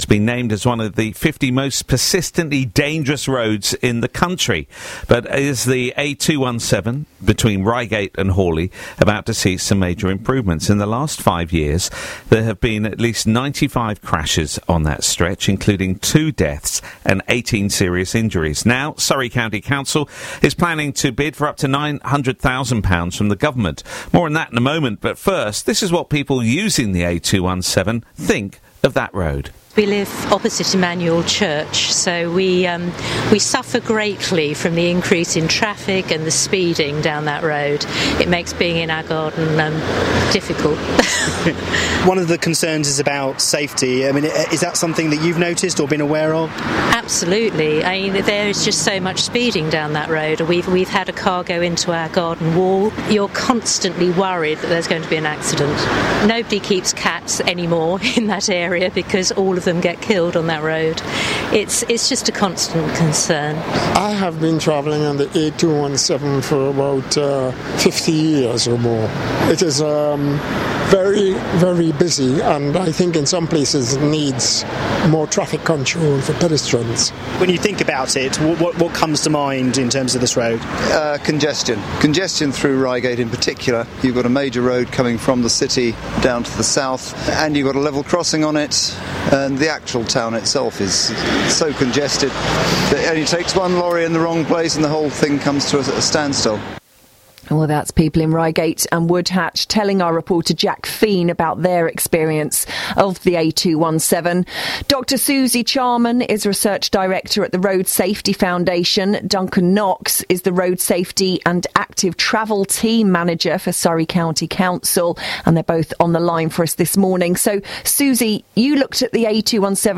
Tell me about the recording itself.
Audio courtesy of BBC Surrey